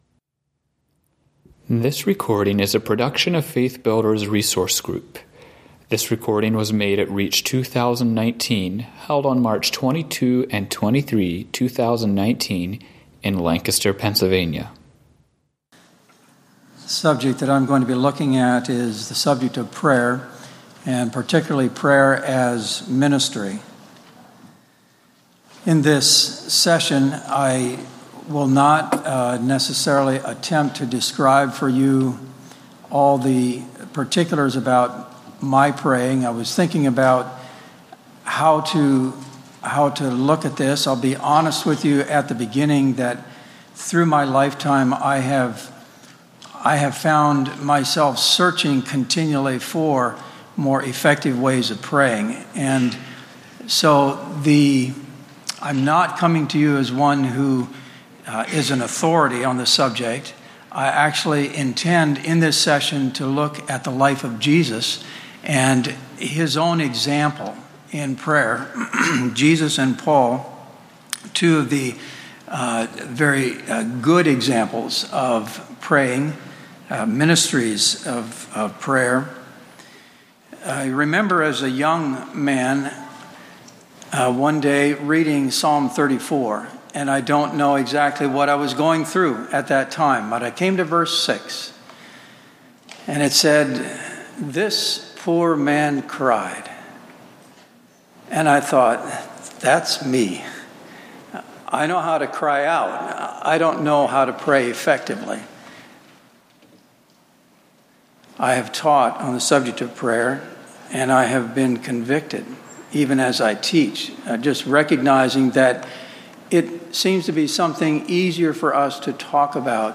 Home » Lectures » Prayer as Ministry